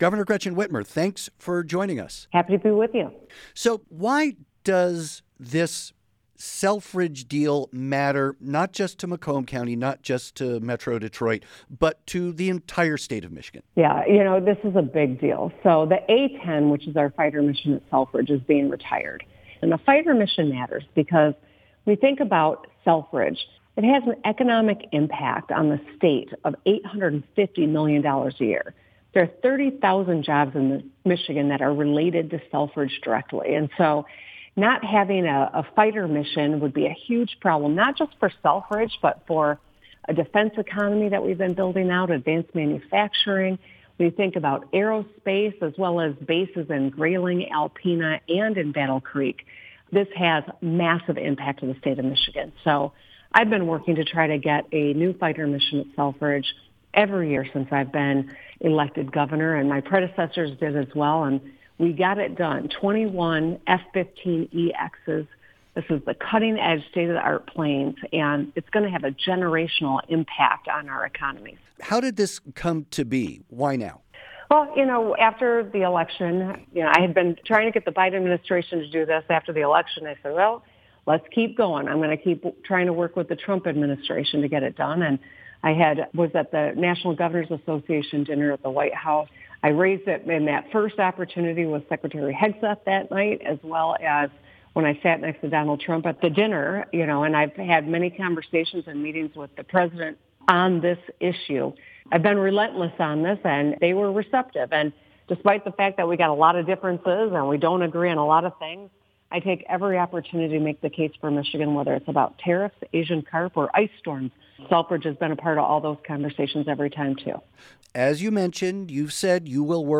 The Michigan Public Radio Network spoke with the governor Wednesday about cutting a deal with a president with whom she’s had an adversarial relationship.
The following interview has been edited for clarity and length.